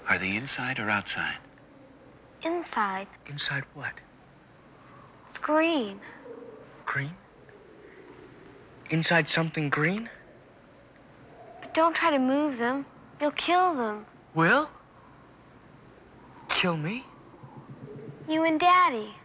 At 14:26 on the DVD, Will and Holly's dialog sounds a bit off. I think one of them must have messed up a line slightly.